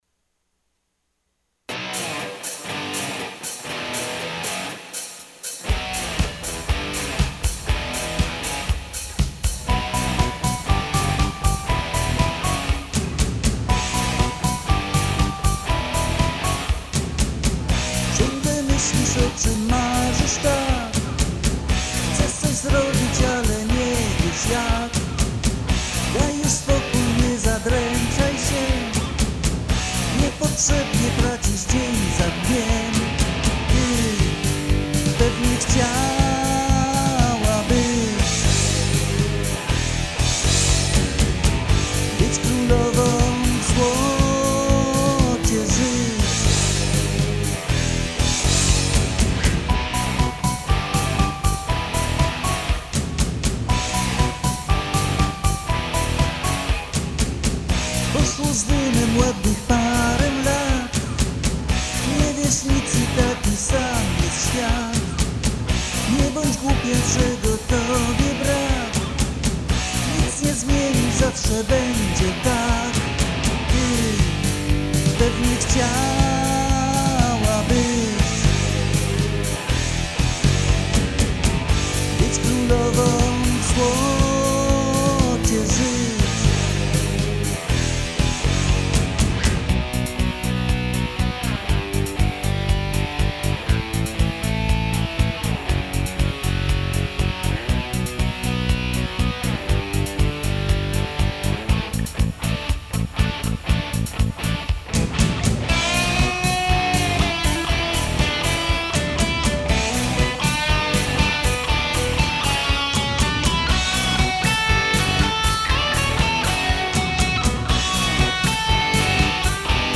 Ich singe "so wie ich singe" auf polnisch daher bei jedem Song ist ein Gesangtext und eine Übersetzung zu deutschen Sprache als *pdf Datei beigefügt.
Im März 1983 verfasste ich den Text und am nächsten Tag war fertig der rockige Song. Die Aufnahme stammt aus dem Jahr 2004 und wurde im April 2020 aufgefrischt.